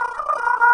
描述：从阴森恐怖的FX包。
Tag: 怪异 女巫 实验 令人毛骨悚然 编辑 吓人 声音 闹鬼 女性 效果 FX 噪音 干燥 阴森恐怖 沉默 爱迪生 蓝色 音调 翻录 困扰